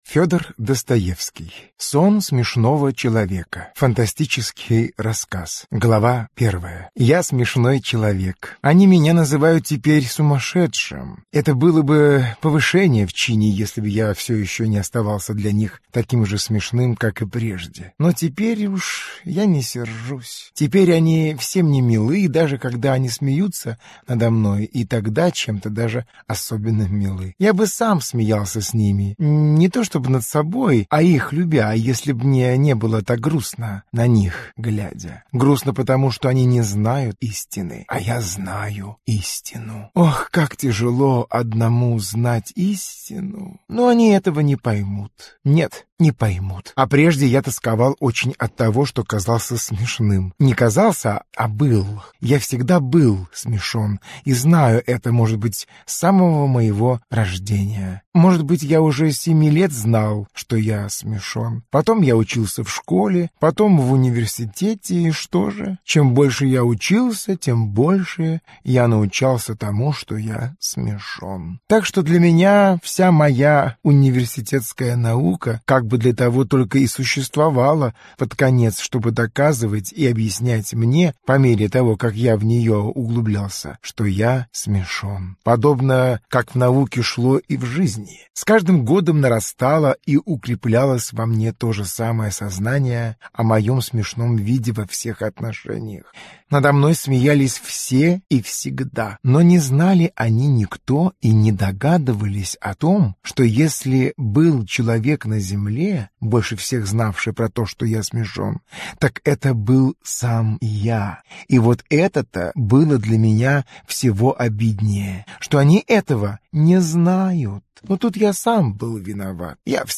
Аудиокнига Сон смешного человека | Библиотека аудиокниг
Aудиокнига Сон смешного человека Автор Федор Достоевский Читает аудиокнигу Валерий Гаркалин.